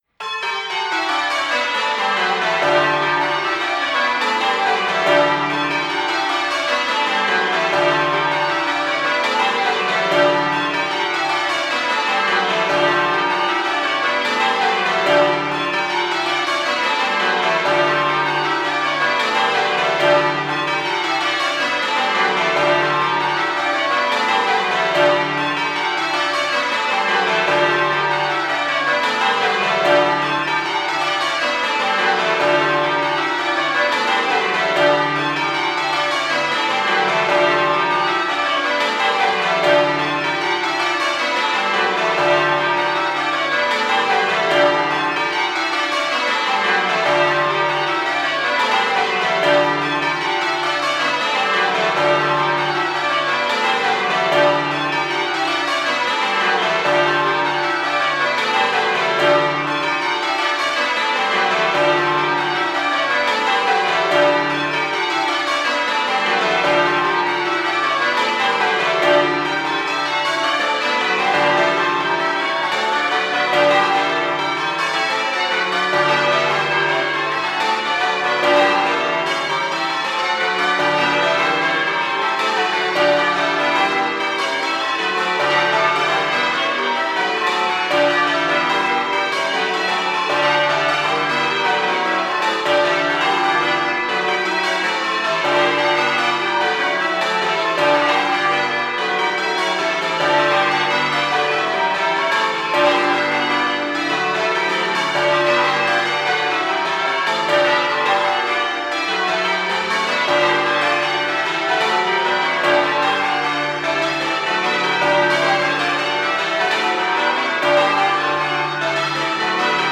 London 12 Bell Striking Competition 2009
Notes on the recordings An AKG C391B microphone was suspended 4� m above the bells and fed an Apogee analogue to digital converter.
These recordings of the feed give a good impression of the bands' performances.